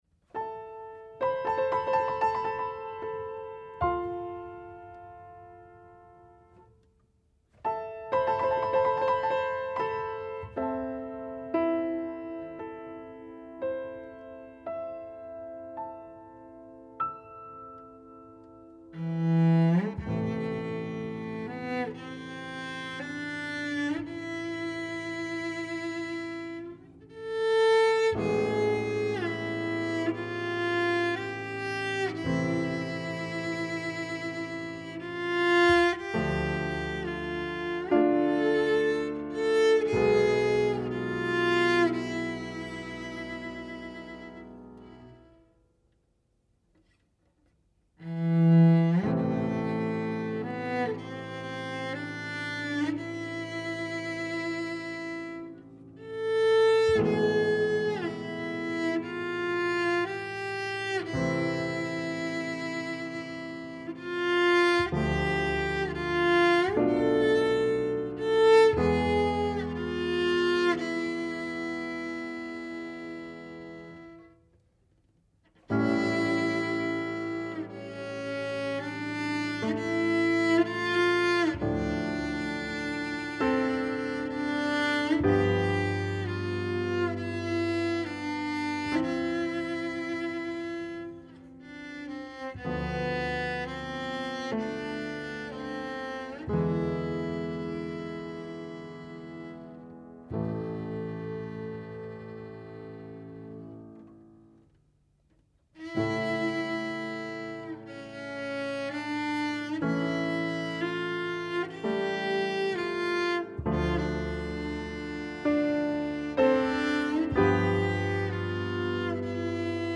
CONCERT RADIOPHONIQUE A L’AUDITORIUM XENAKIS DE STAINS
En partenariat avec le CMMD de Stains, émission enregistrée en public à l’auditorium Xénakis.
violoncelle
piano